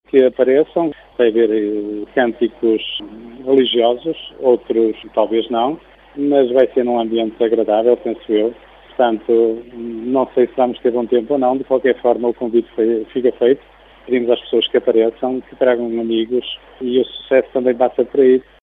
O presidente de Junta deixa ainda o convite a todos.